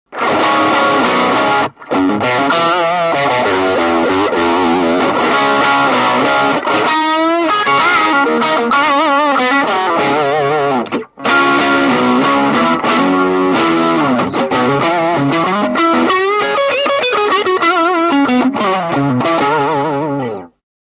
Vintage Fuzz and Distortion Blender - Bold Distortion
- Vintage Fuzz and Traditional Distortion
Demo with Humbucker Pickup 1